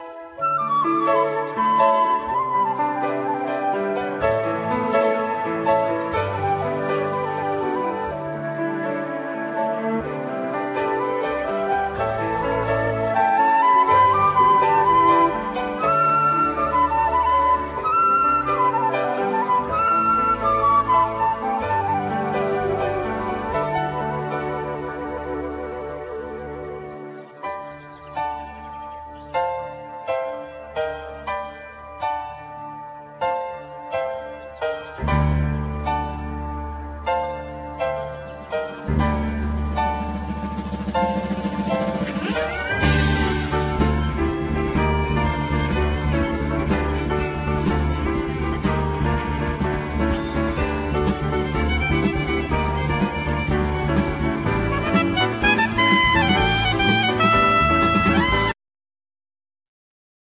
Piano,Synthsizer
El.+Ac. violin
Saxophone,Flute
El.guitar
Drums
Tabla